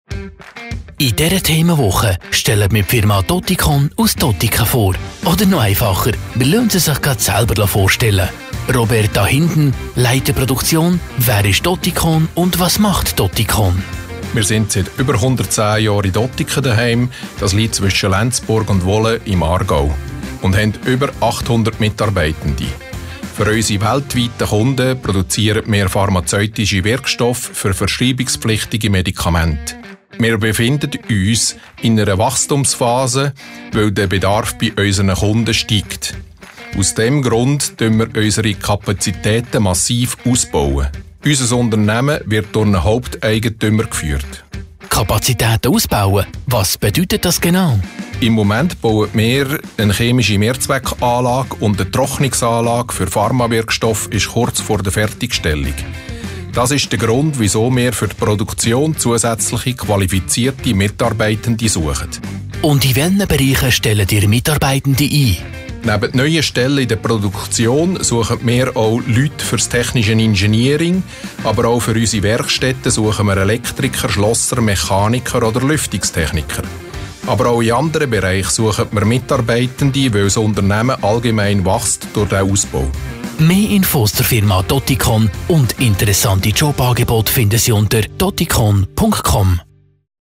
Infomercial Week